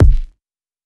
Kick (10).wav